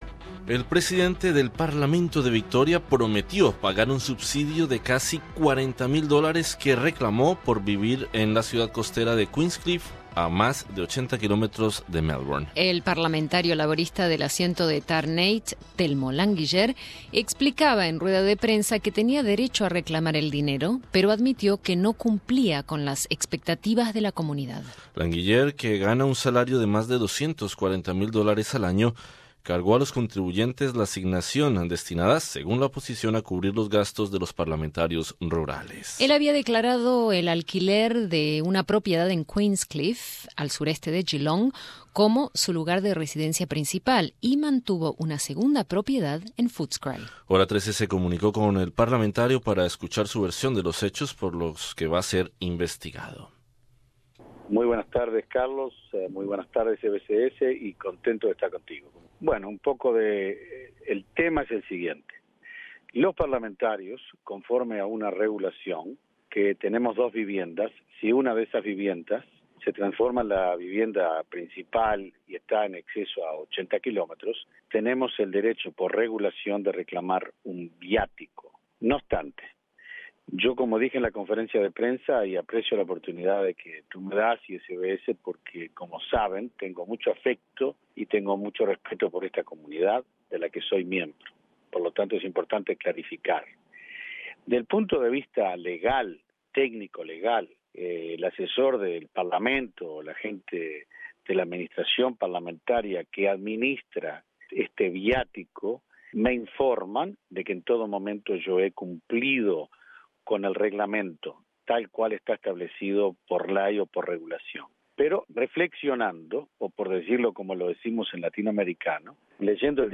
El presidente del parlamento de Victoria prometió pagar un subsidio de casi 40.000 dólares que reclamó por vivir en la ciudad costera de Queenscliff, a más de 80 kilómetros de Melbourne. El parlamentario laborista del asiento de Tarneit, Telmo Languiller, explica a Hora 13 que tenía derecho a reclamar el dinero, pero admitió que no cumplía con las expectativas de la comunidad.